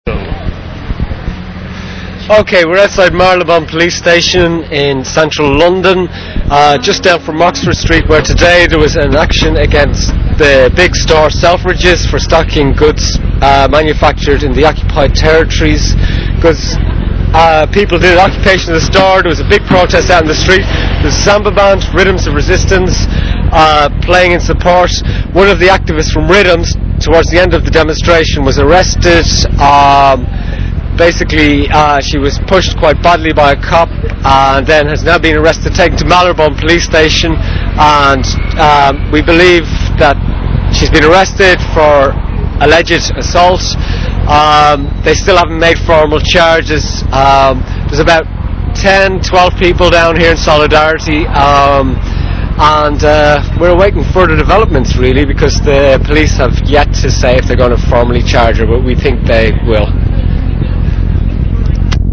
demonstrators gather outside marylebone cop shop (audio)
after todays actions at Selcoffins, activists and friends gather outside marylebone police station in solidarity with arested rythms of resistance sambista...